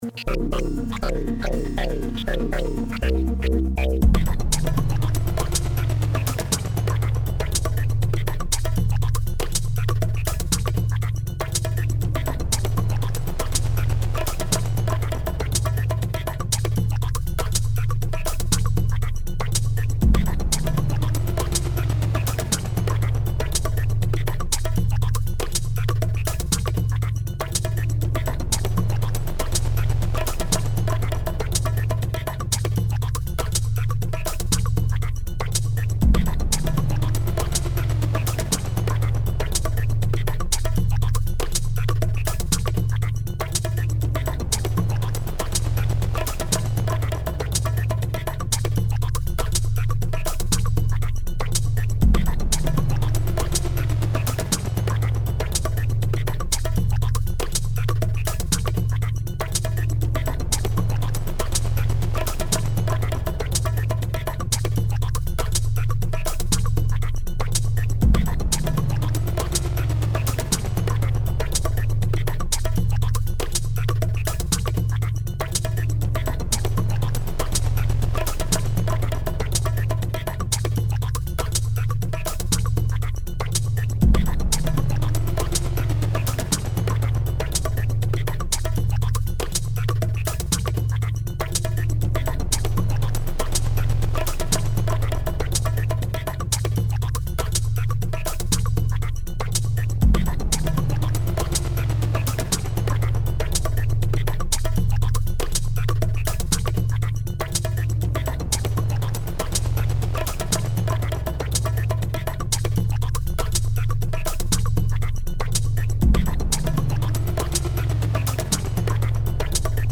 緊迫感のある静かなBGMです。